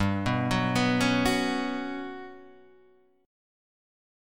GM7b5 Chord